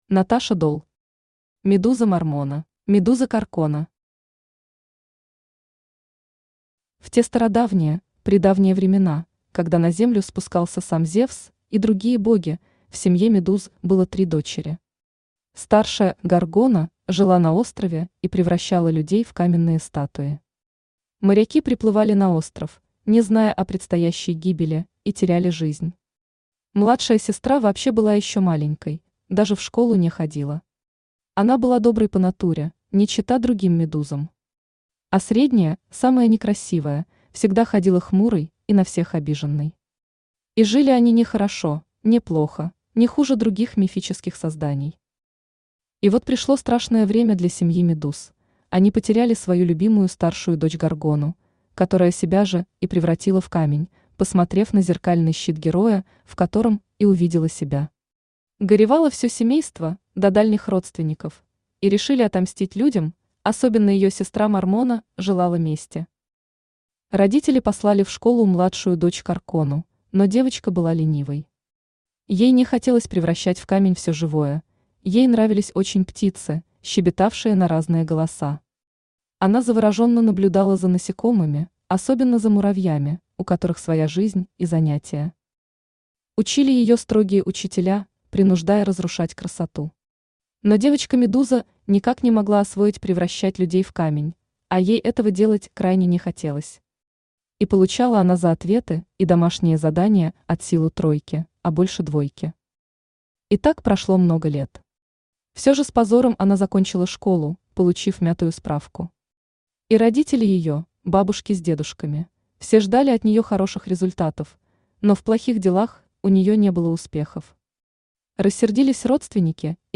Аудиокнига Медуза Мормона | Библиотека аудиокниг
Aудиокнига Медуза Мормона Автор Наташа Дол Читает аудиокнигу Авточтец ЛитРес.